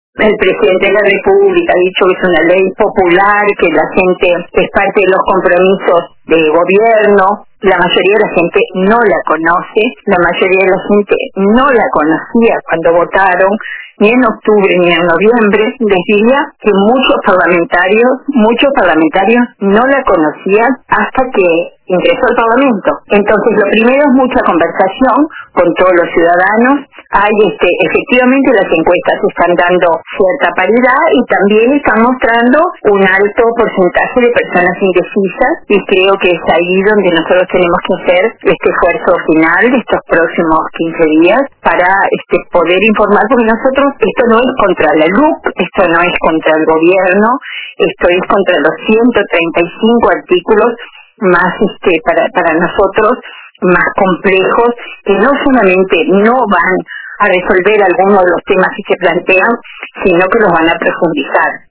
La senadora frenteamplista y ex ministra de Turismo, Liliam Kechichian, participó este viernes en LA TARDE DE RBC